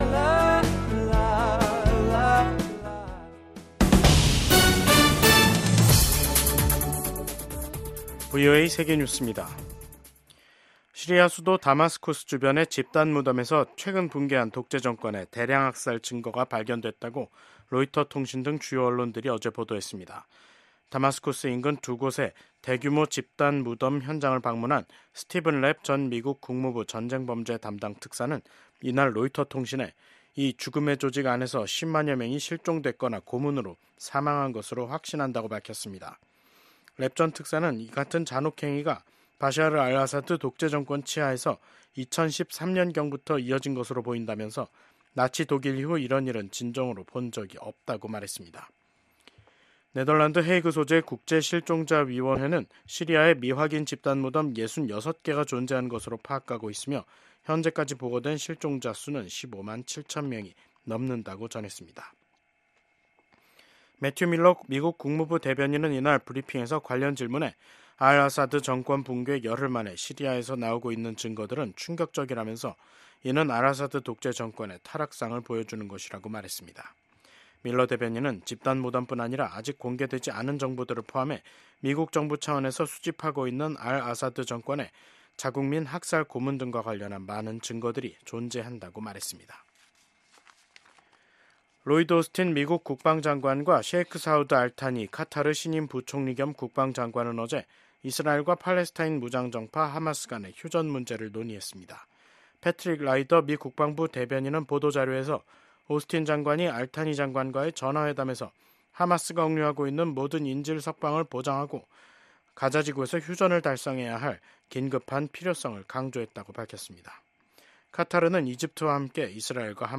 VOA 한국어 방송의 간판 뉴스 프로그램입니다. 한반도와 함께 미국을 비롯한 세계 곳곳의 소식을 빠르고 정확하게 전해드립니다. 다양한 인터뷰와 현지보도, 심층취재로 풍부한 정보를 담았습니다.